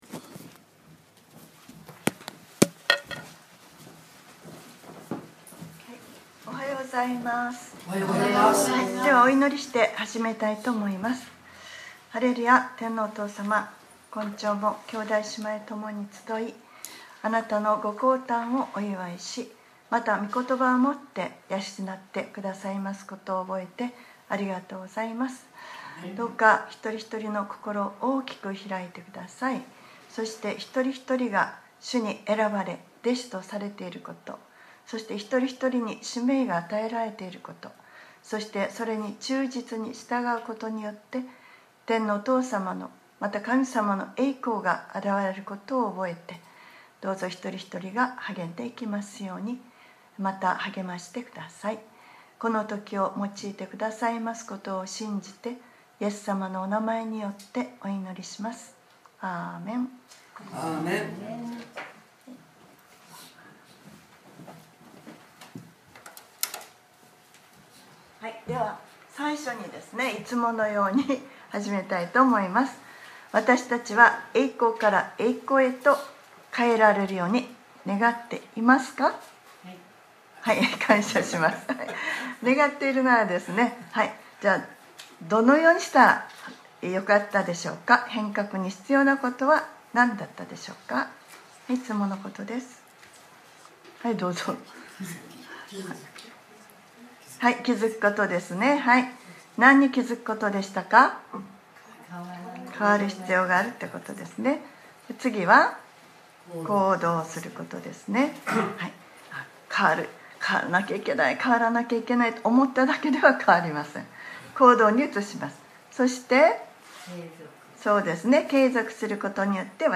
2019年12月15日（日）礼拝説教『本当のクリスマス』